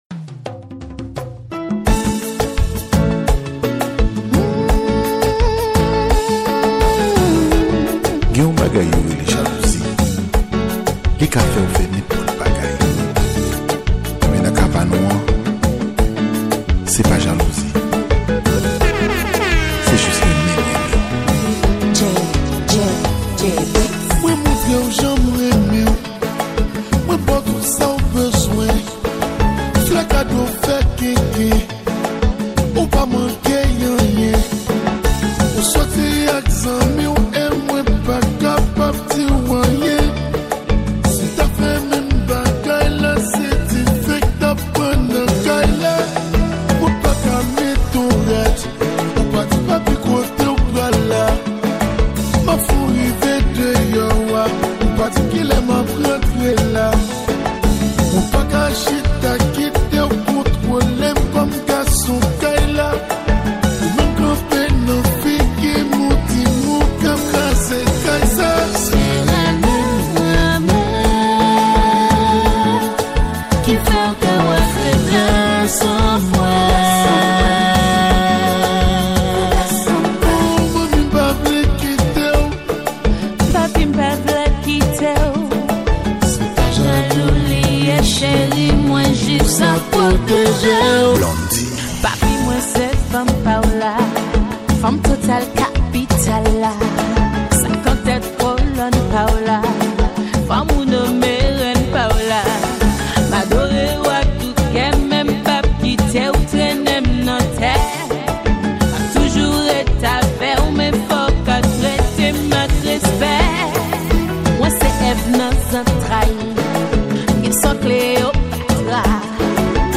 Genre : MIXES